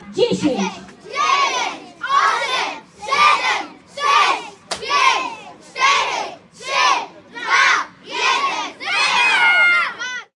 描述：是一个男声用波兰语说"Numer X"。 用Zoom H2通过USB录制。用Audacity编辑和去噪。
标签： 声乐 波兰语 雄性 抛光
声道立体声